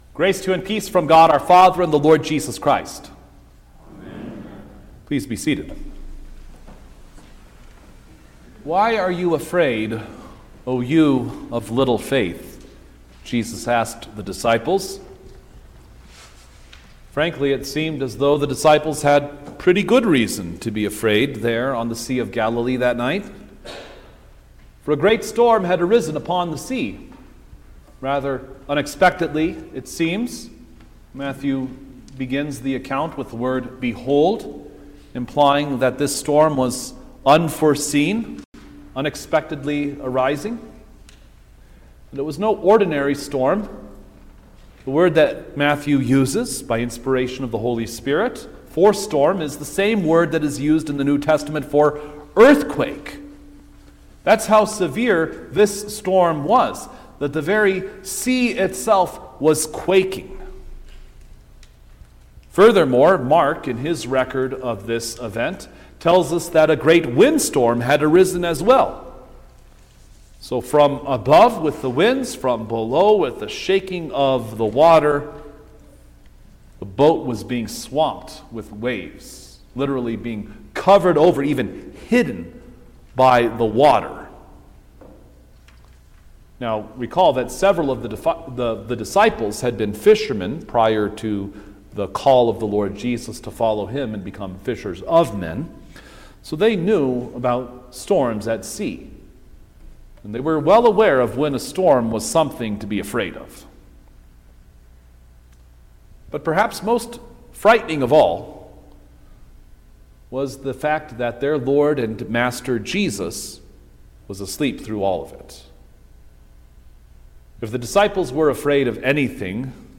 February-2_2025_Fourth-Sunday-after-the-Epiphany_Sermon-Stereo.mp3